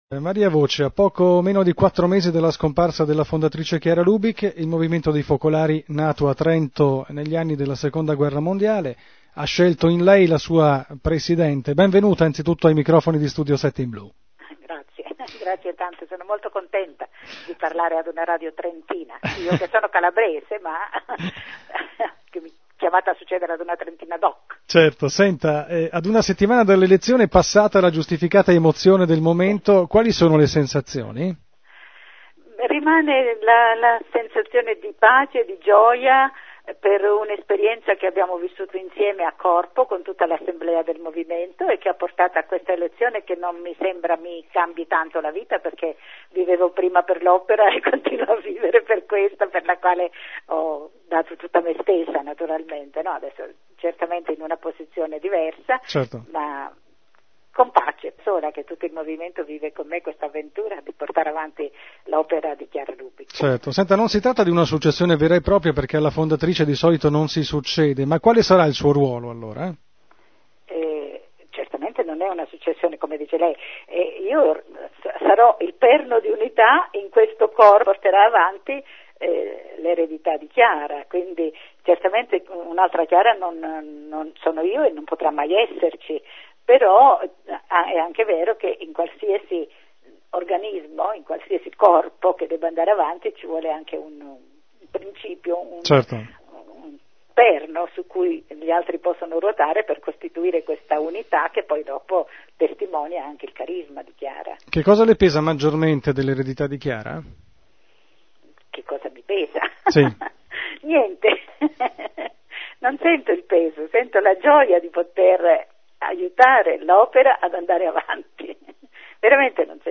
intervista a Radio Studio 7 (Trento)